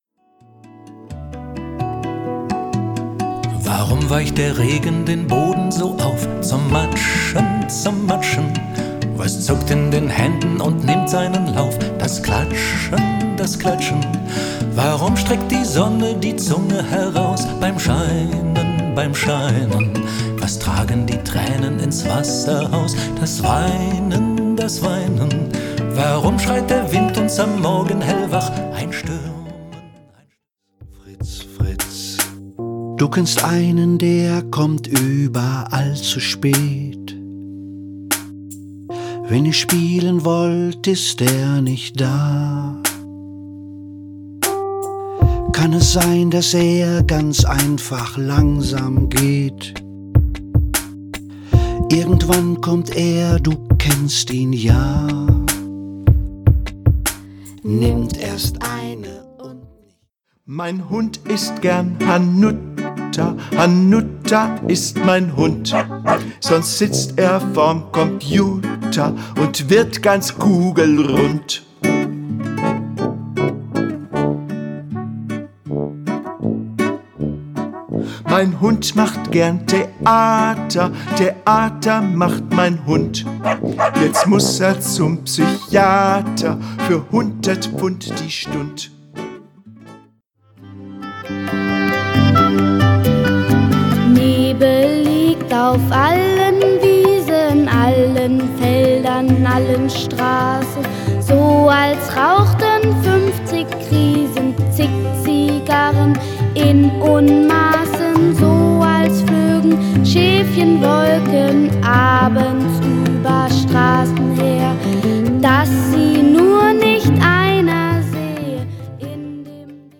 Themenwelt Kinder- / Jugendbuch Gedichte / Lieder